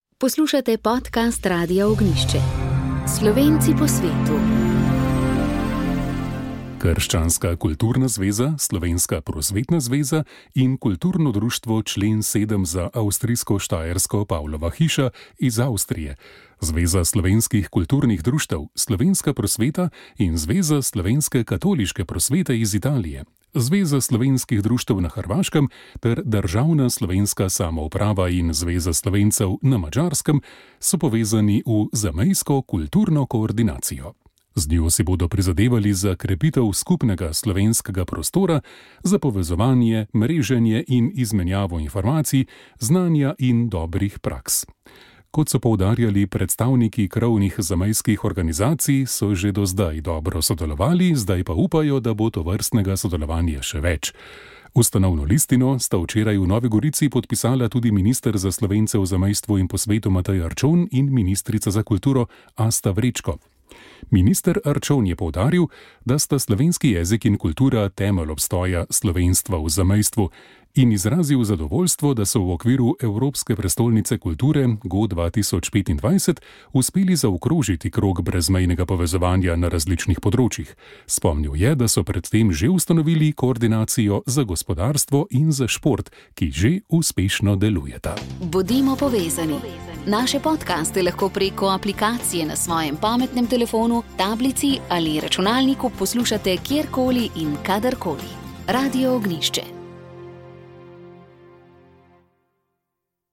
V nedeljski kmetijski oddaji smo se ustavili ob vprašanjih, ki so jih sprožile minule hudourniške poplave, ki so zelo prizadele tudi kmetije in kmetijsko pridelavo. Pripravili smo prispevek o zapletih pri izdaji odločb za povračilo škode po lanski suši, v oddaji pa je bil naš gost tudi predsednik komisije za kmetijstvo v Državnem svetu Branko Tomažič.